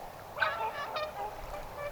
laulujoutsenen poikasen ääni
laulujoutsenen_poikasen_aani.mp3